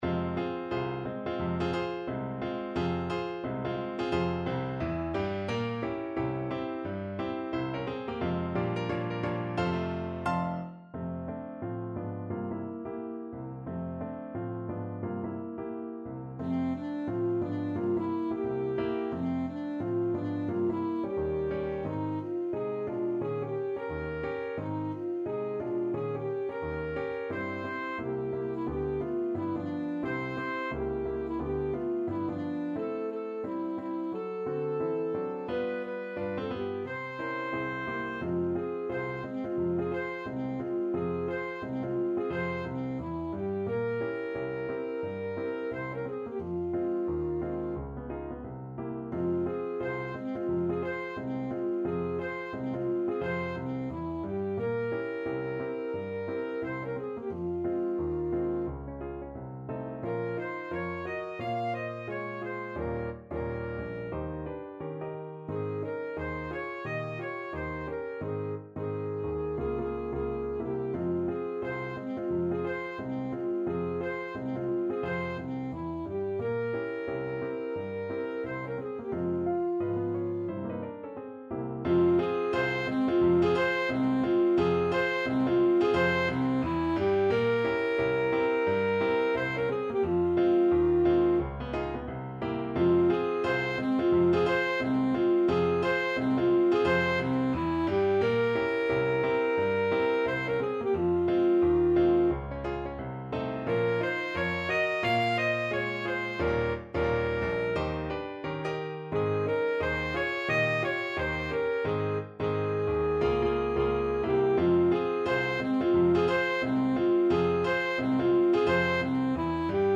Alto Saxophone
~ = 176 Moderato
2/2 (View more 2/2 Music)
Jazz (View more Jazz Saxophone Music)